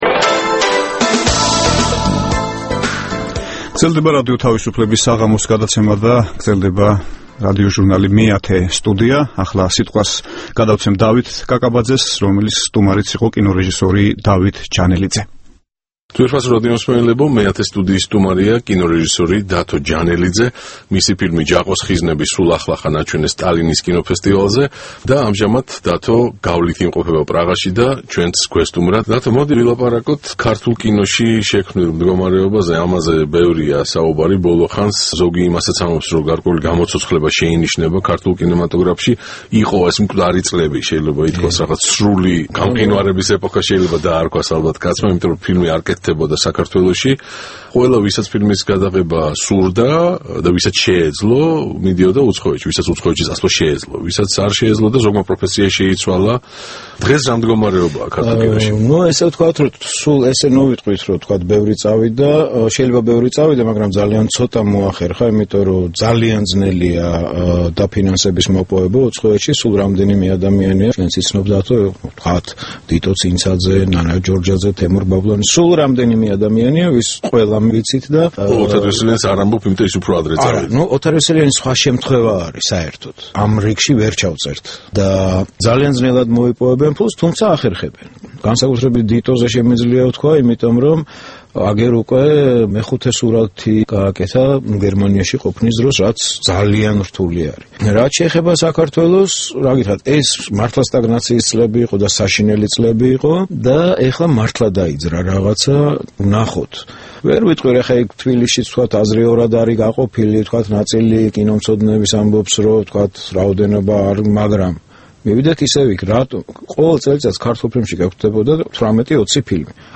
ინტერვიუ კინორეჟისორთან